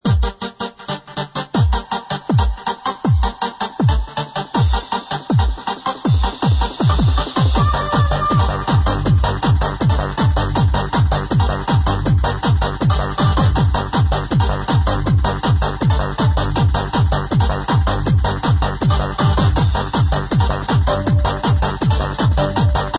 amazing classic hard-trance needs id #3
vocals say "colour of love" i think